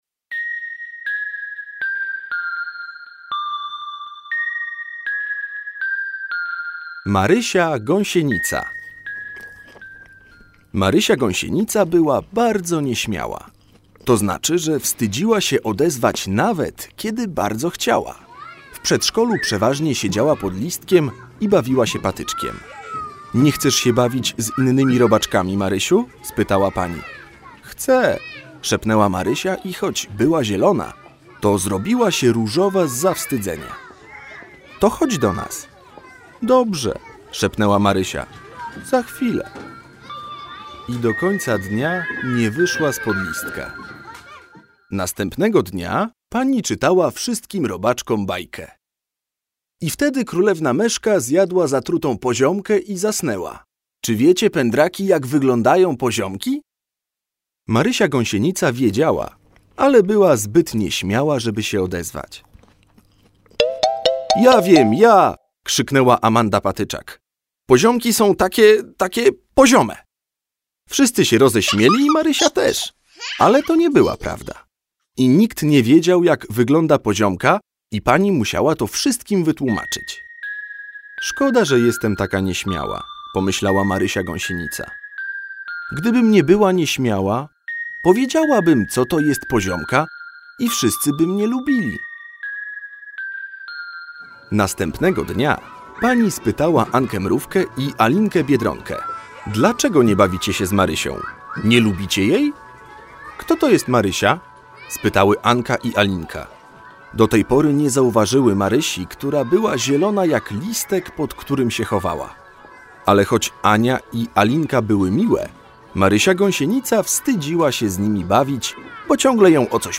audiobook bajki bajkiterapeutyczne emocje